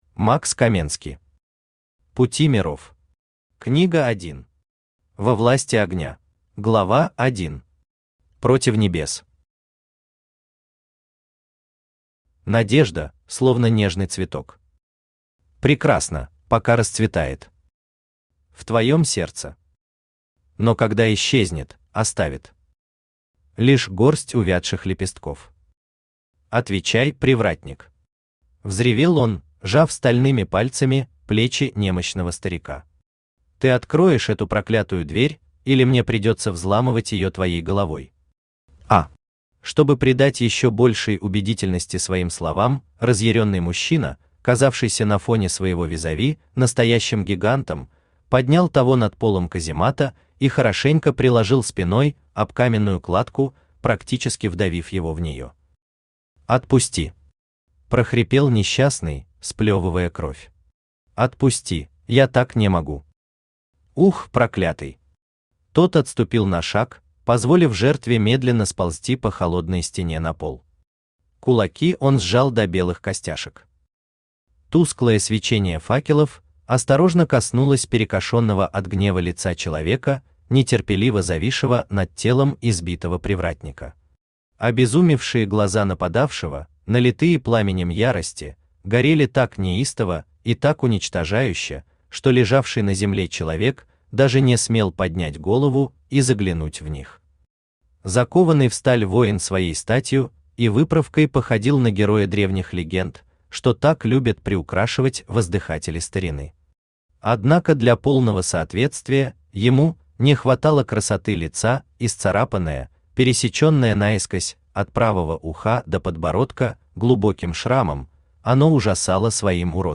Аудиокнига Пути миров. Книга 1. Во власти огня | Библиотека аудиокниг
Во власти огня Автор Макс Каменски Читает аудиокнигу Авточтец ЛитРес.